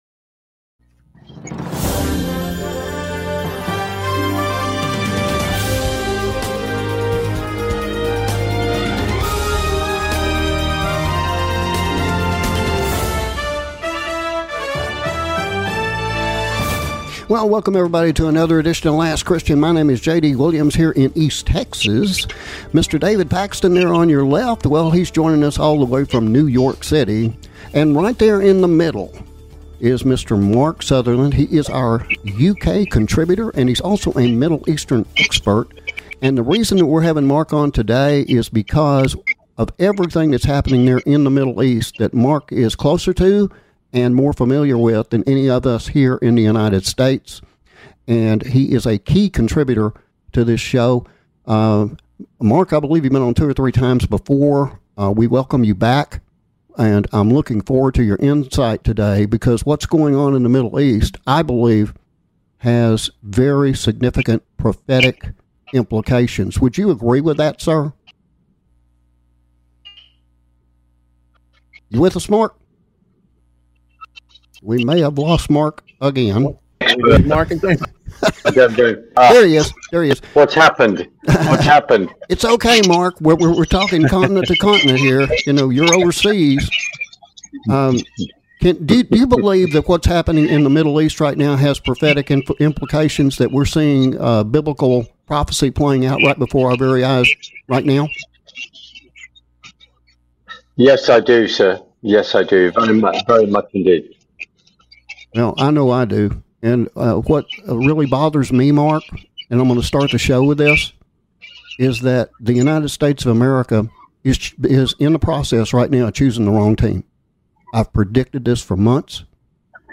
We also apologize for the Audio issues experienced primarily in the first half hour of our International Syndicated Presentation.